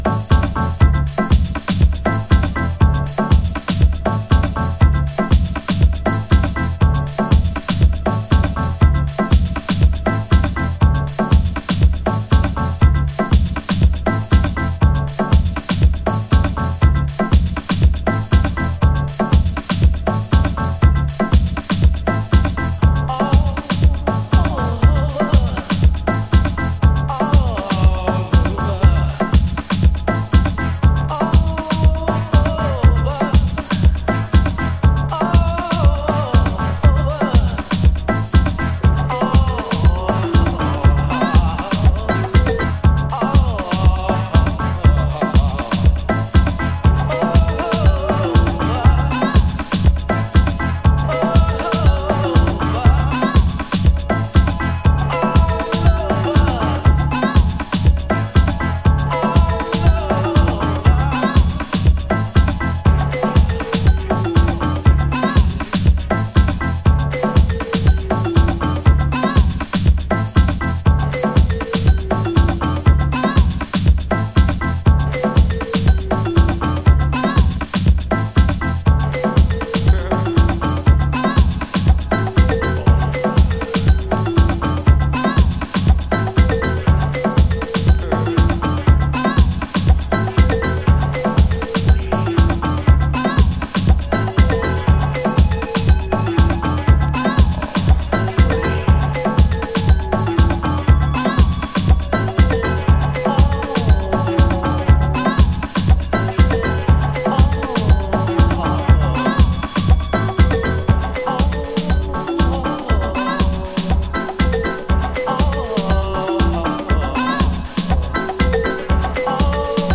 Early House / 90's Techno
NY初期ハウス古典。
オールドスクール & ストリクトリーなハウスグルーヴが楽しめますよ。